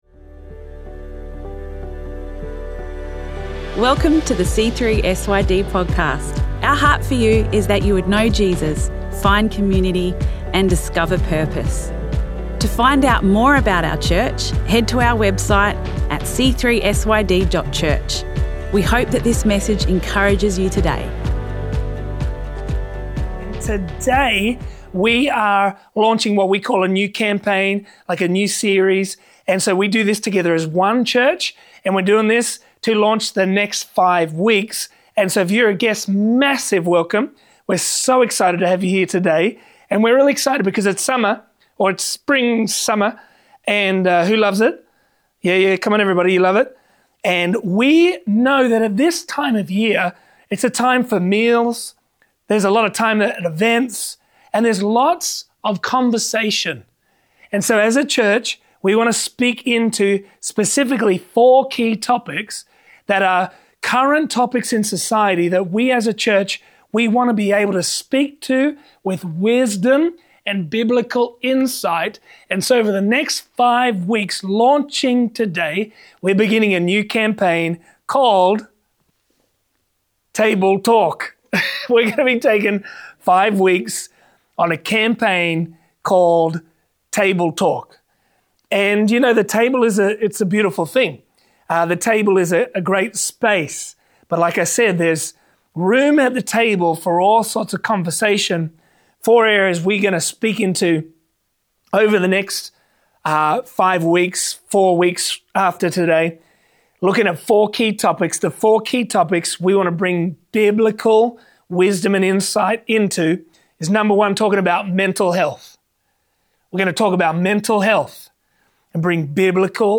PRE-RECORDED_PREACH_-_Audio.mp3